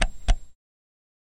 Звук кнопки бипера при нажатии